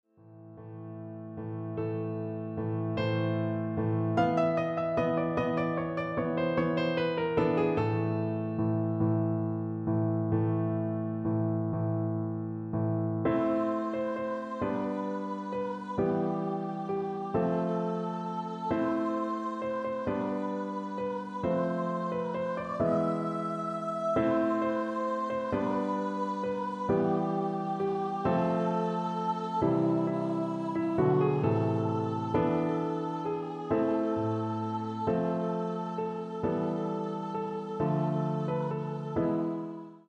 This excerpt is from just before the voice’s entry.